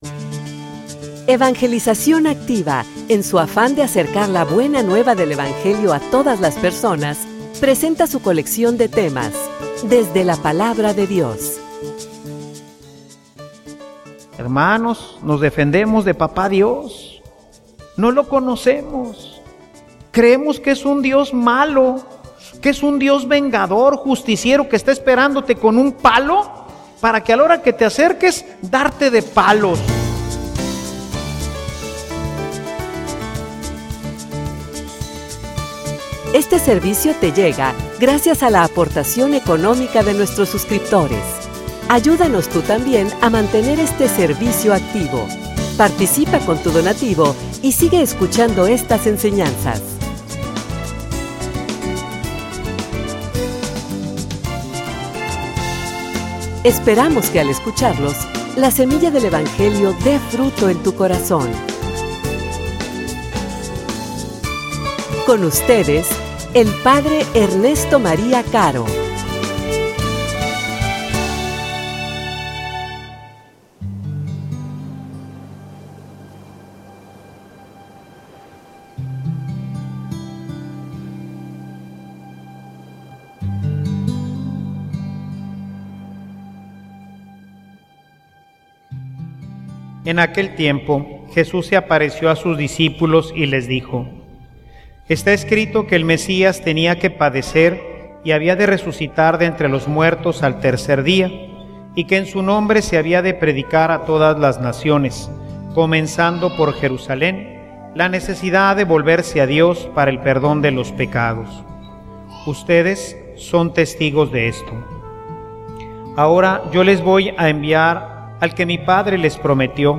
homilia_Ustedes_son_testigos_de_esto.mp3